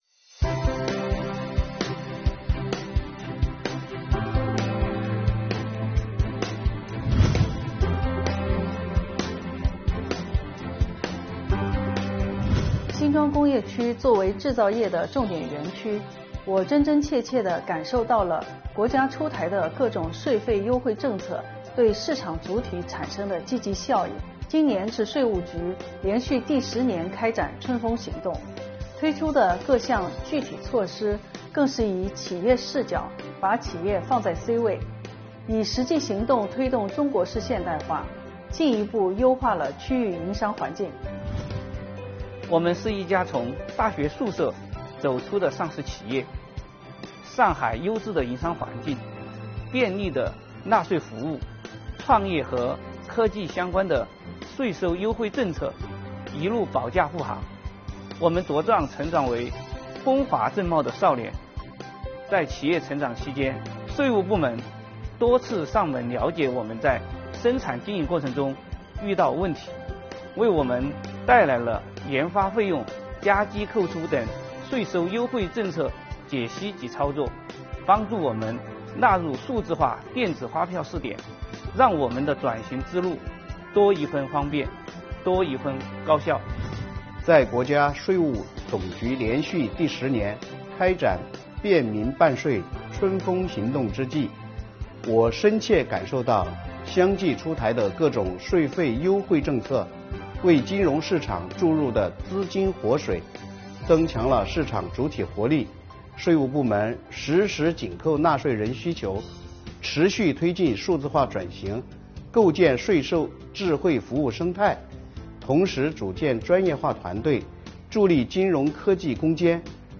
今年，以推进2023年“便民办税春风行动”为契机，开展了“两会代表话春风”活动，邀请了两会代表委员畅谈对近年来“春风行动”开展情况的感受，让我们来听听代表怎么说~